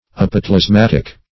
Apotelesmatic - definition of Apotelesmatic - synonyms, pronunciation, spelling from Free Dictionary
Apotelesmatic \Ap`o*tel`es*mat"ic\, a. [Gr. ?, fr. ? effect of